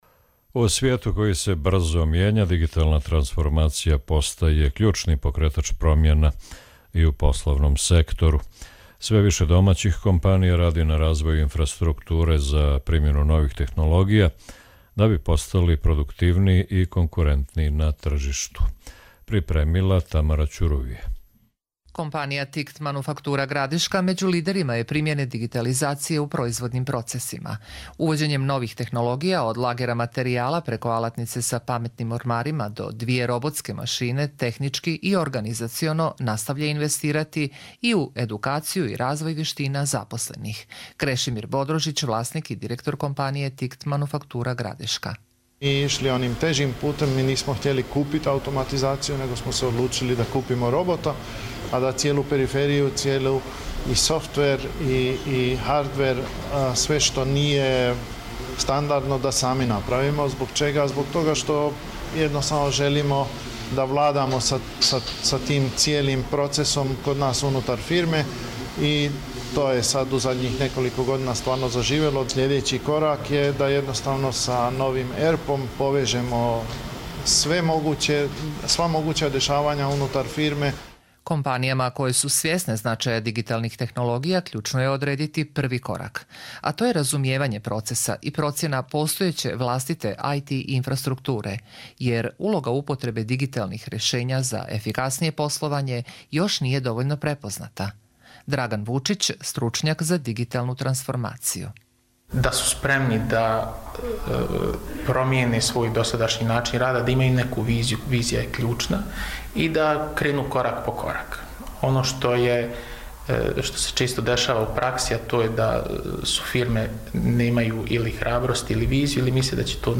Radio reportaža